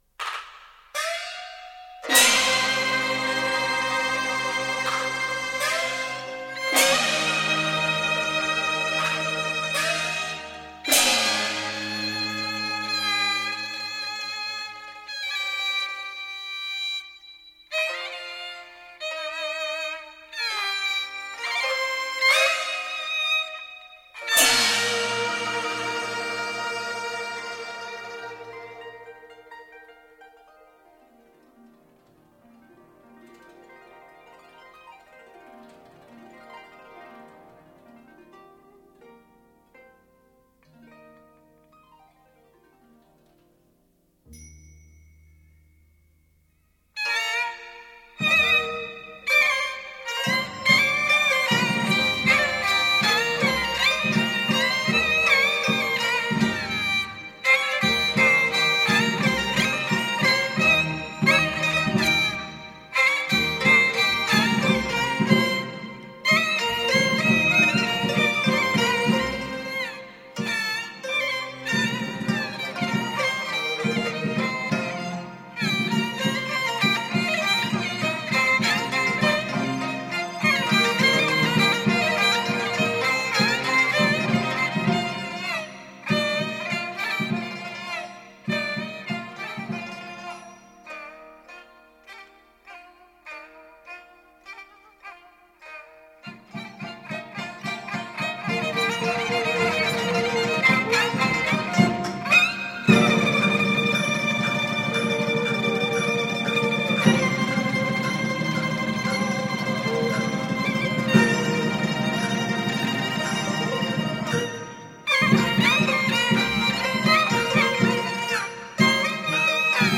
胡琴演奏
其演奏音质纯正结实、穿透力强；音色润厚，具有别致动听、扣人心弦的艺术感染力。
这是一首京剧曲牌。京胡是京剧的主要伴奏乐器。